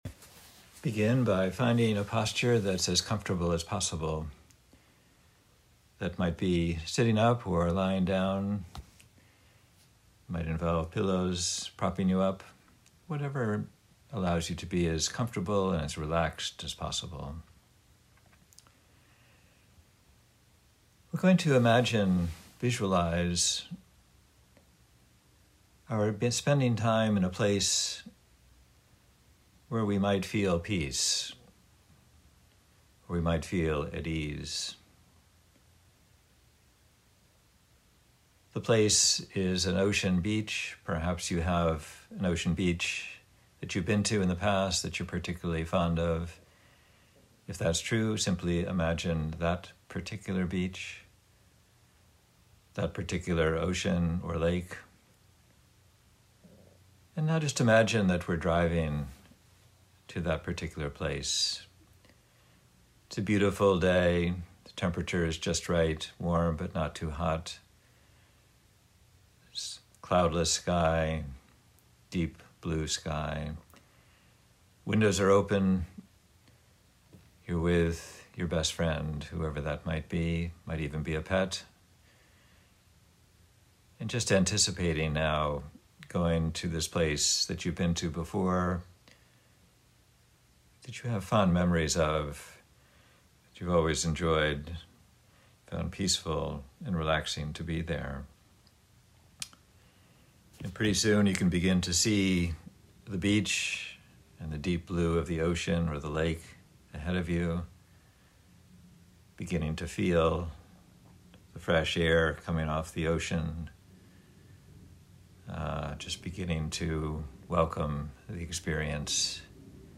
Meditation
visualization-at-a-beach-17-minutes.mp3